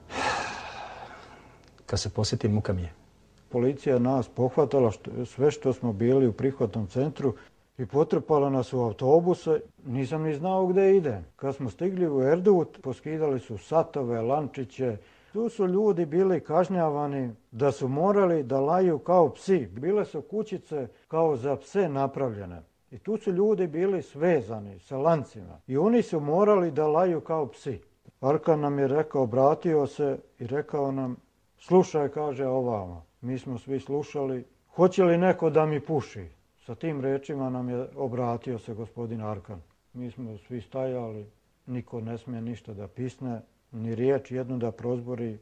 Svedočanstvo iz serijala "Jedinica"